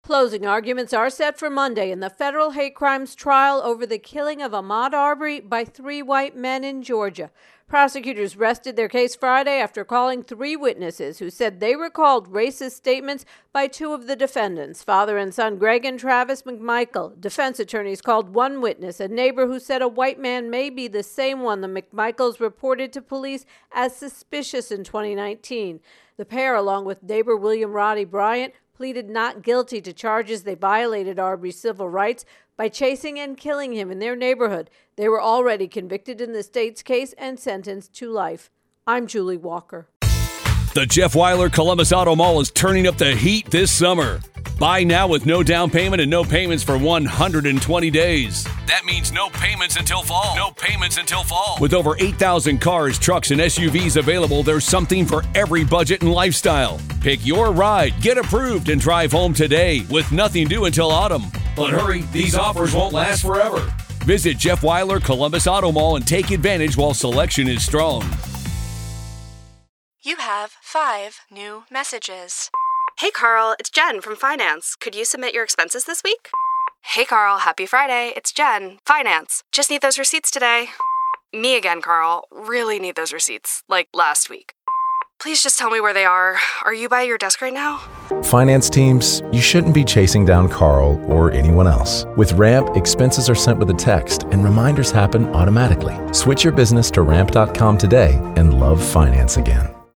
intro and voicer